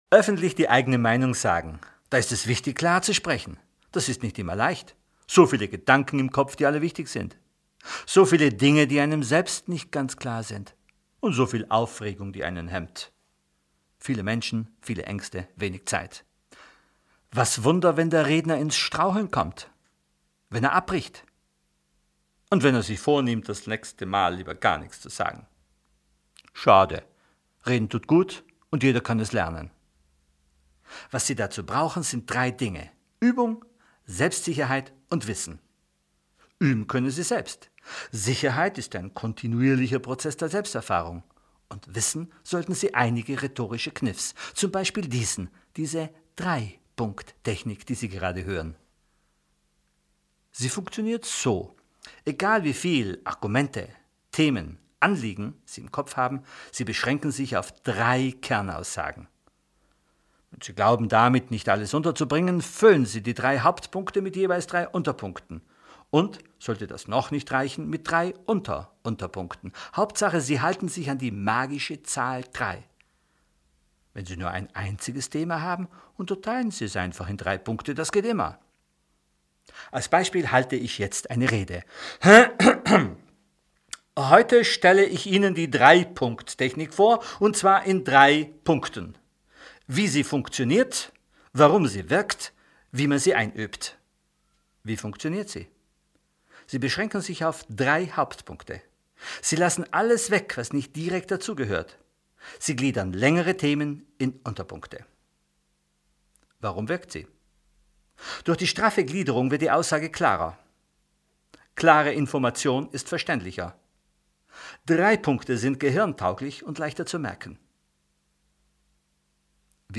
Lassen Sie sich den Text vorlesen, ohne die Ziffern auszusprechen – stattdessen kleine Stau-Pausen lassen!